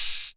Open Hat [13].wav